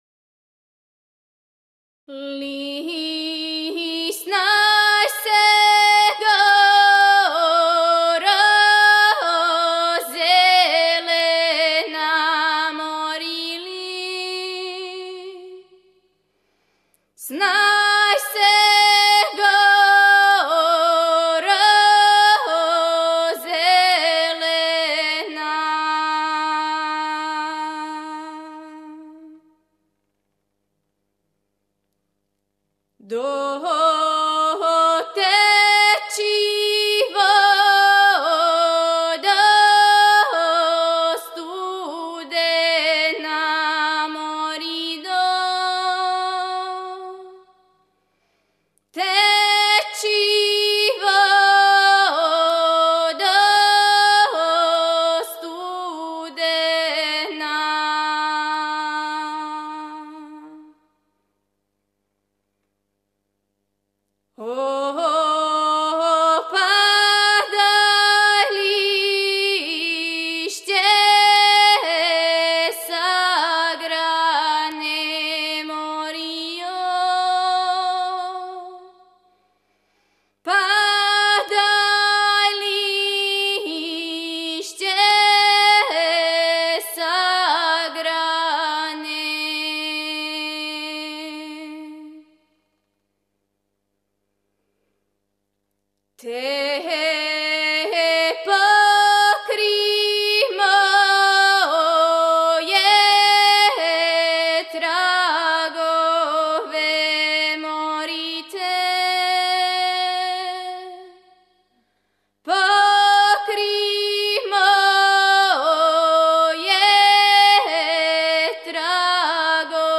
Порекло песме: Село Штрпце, Косово
Напомена: Љубавна песма